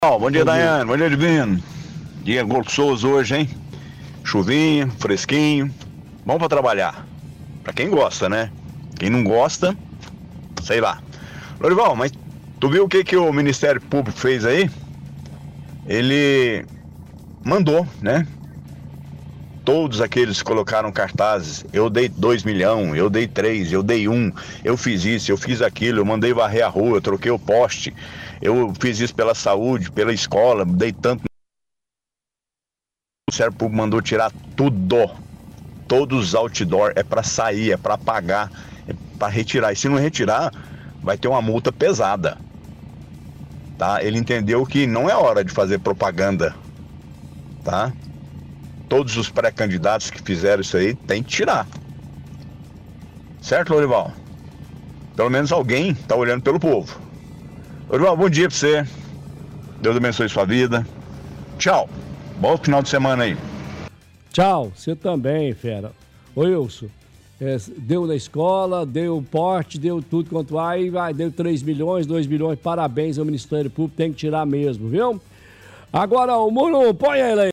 – Ouvinte, relata sobre o mesmo assunto. Parabeniza MP pela iniciativa.